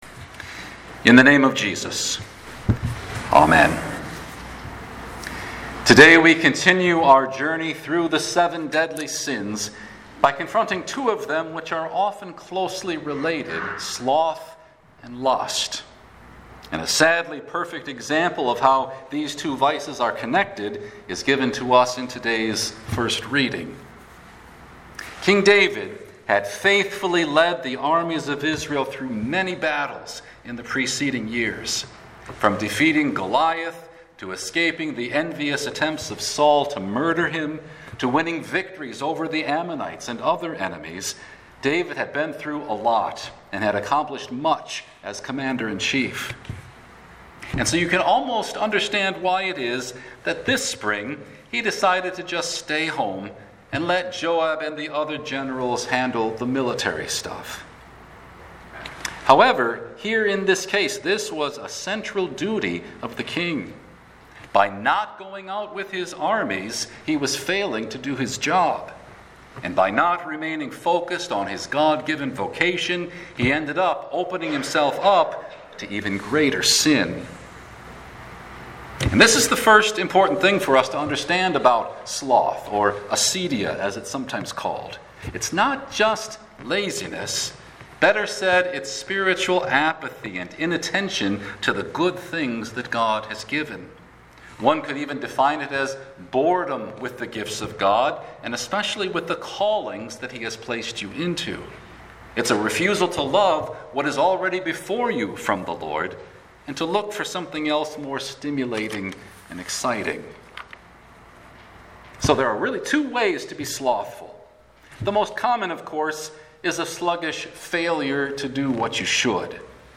Midweek-Lent-3-Sloth-and-Lust.mp3